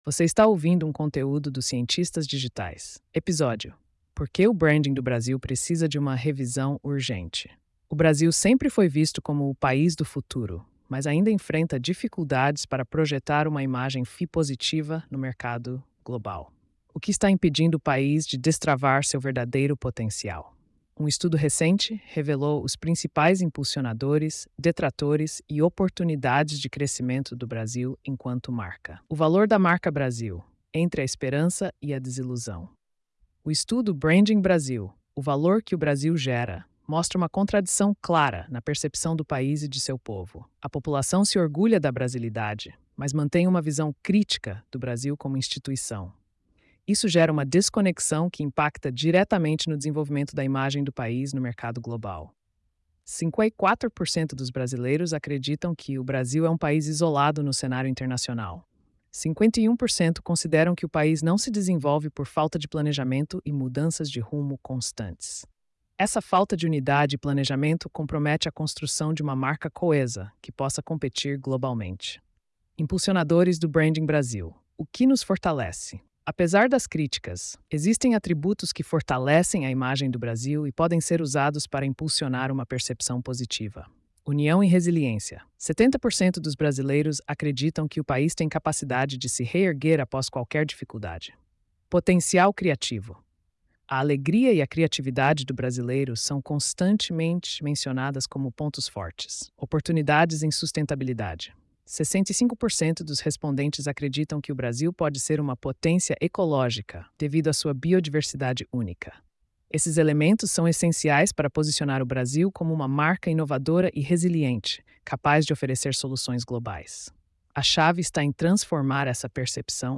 post-2561-tts.mp3